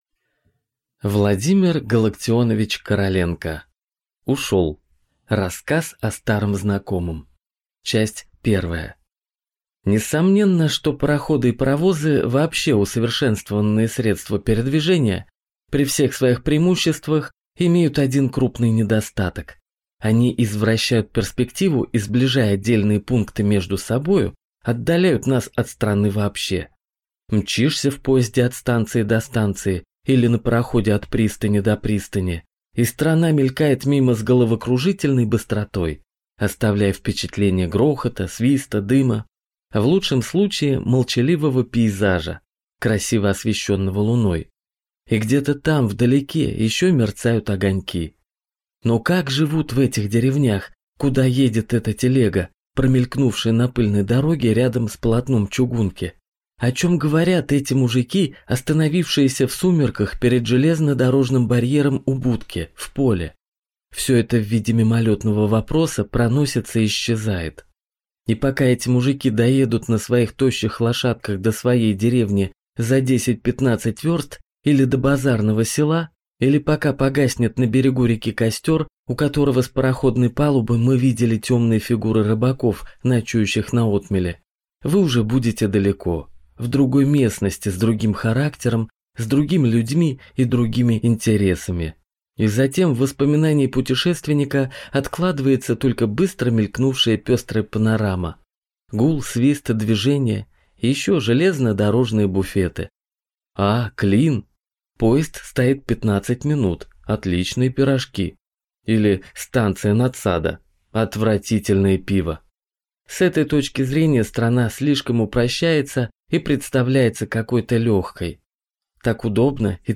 Aудиокнига Ушел! Автор Владимир Короленко